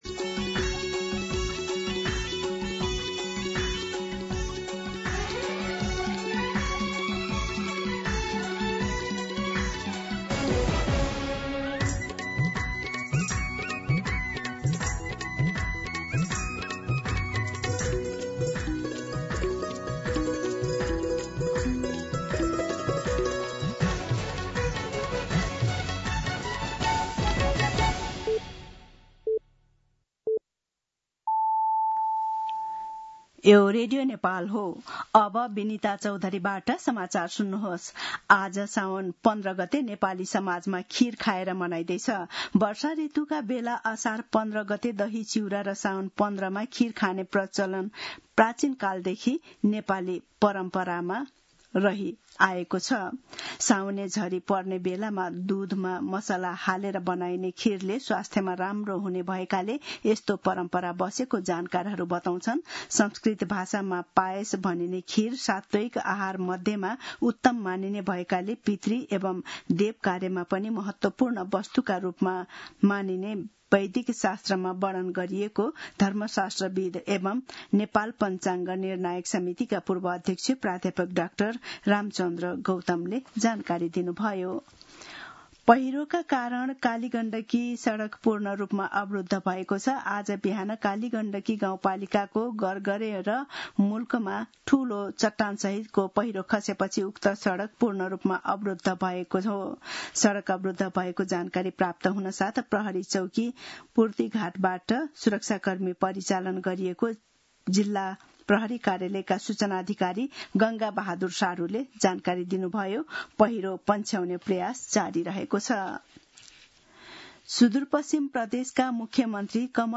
मध्यान्ह १२ बजेको नेपाली समाचार : १५ साउन , २०८२